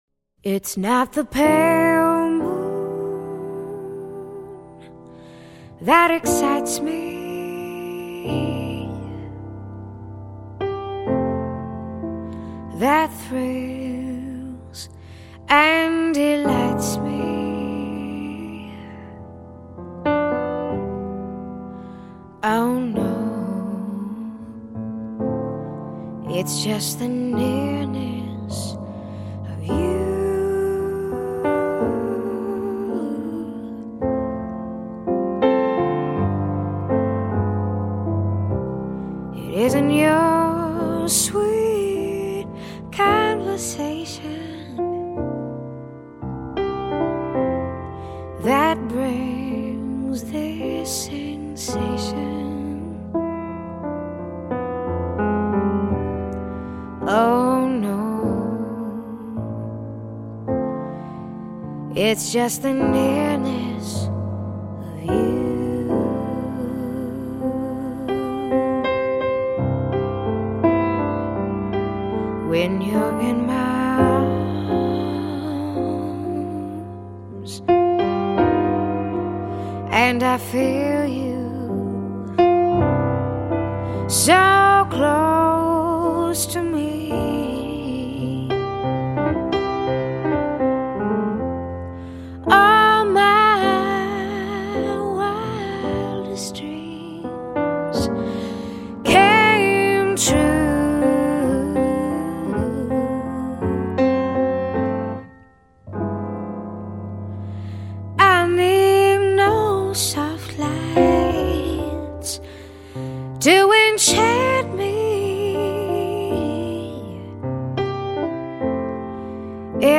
音乐类型：爵士乐
慵慵懒懒的爵士轻乐,放松心思,一杯咖啡,作沙发聆听....